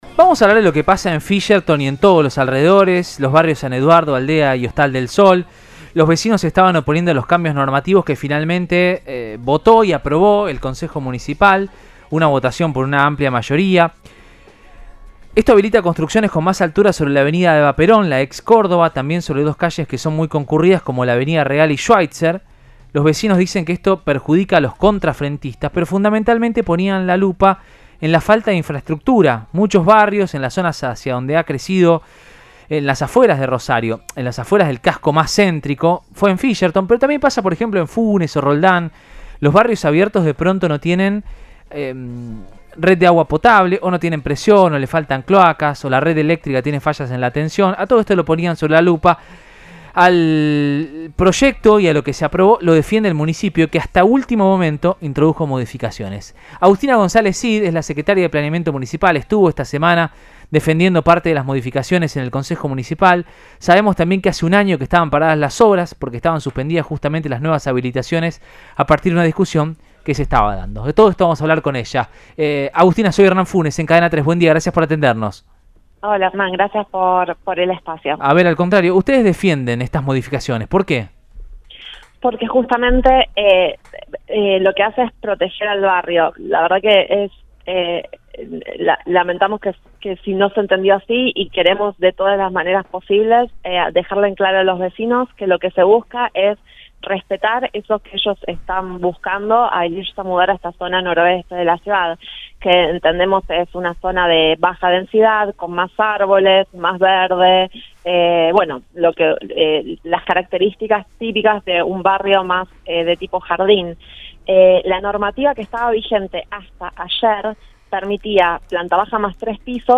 Agustina Gonzalez Cid, Secretaria de Planeamiento municipal le dijo a Siempre Juntos de Cadena 3 Rosario que “con estas modificaciones queremos proteger al barrio y respetar a los vecinos”.